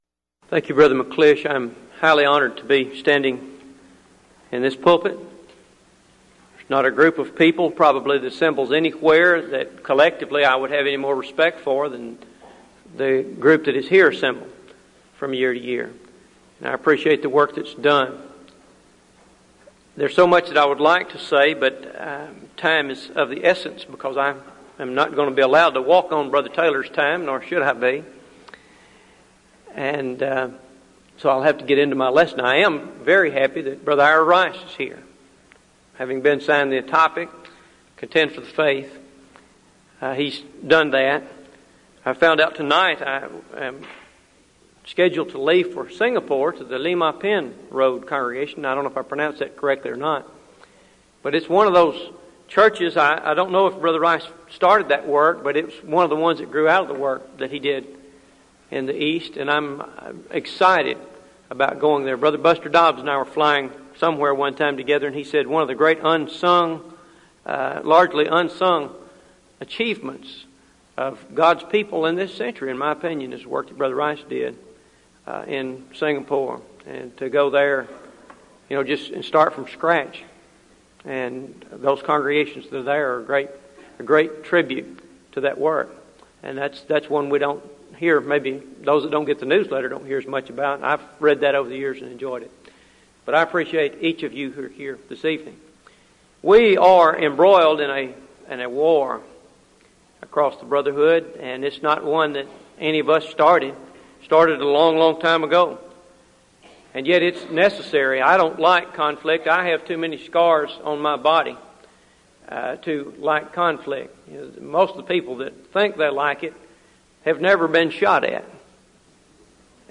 Event: 1998 Denton Lectures